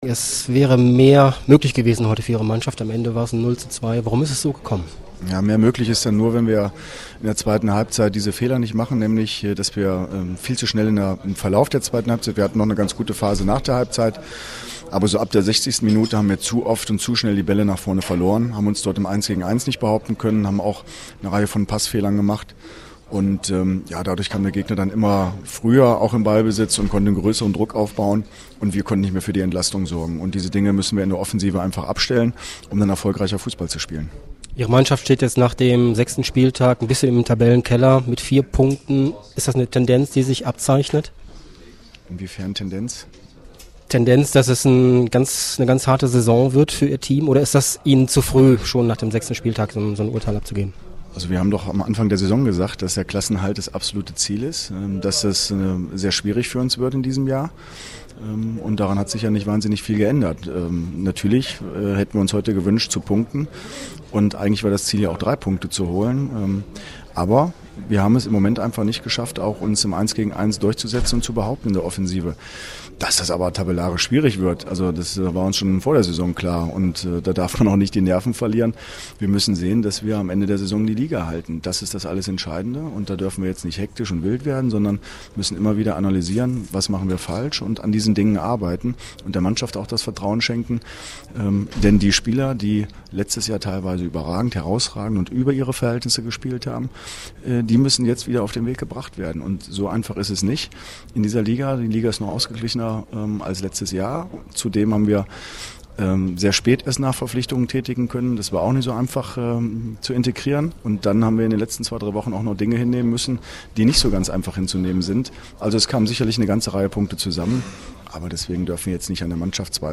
AUDIOKOMMENTAR
Chef-Trainer Andre Schubert zum Spiel